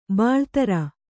♪ bāḷtara